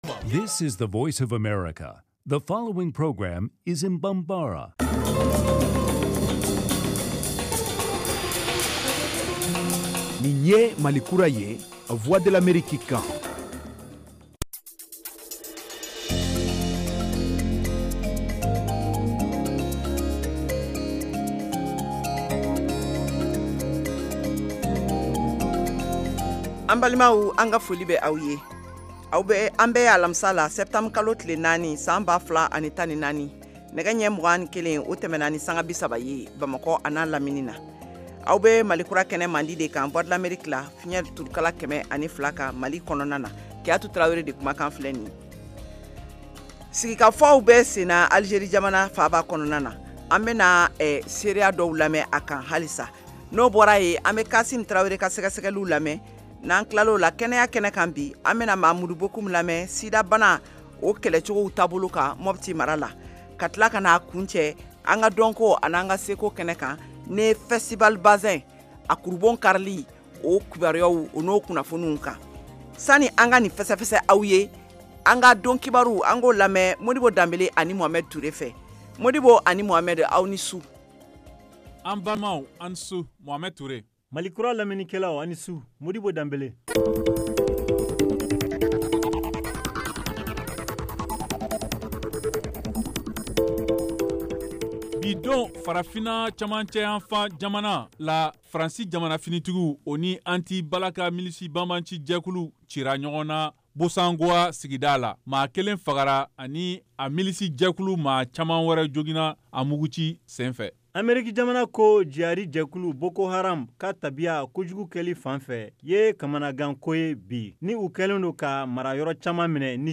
en direct de Washington